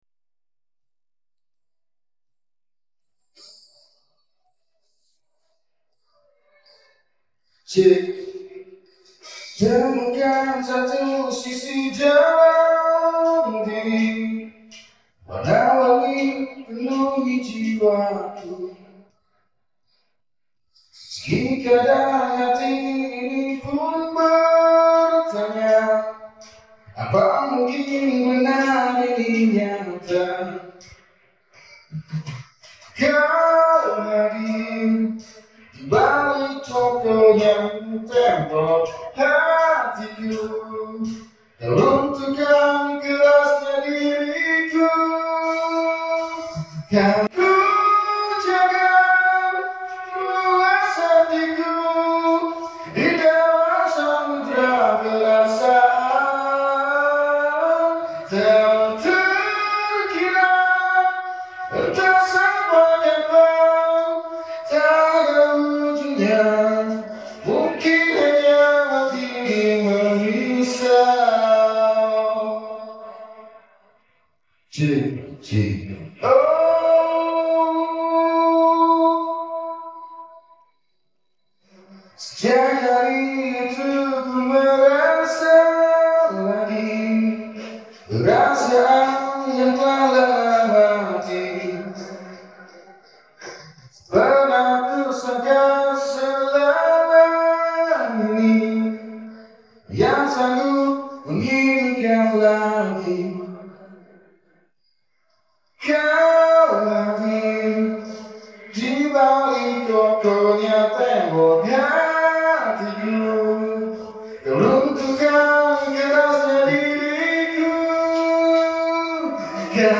Phần giọng nói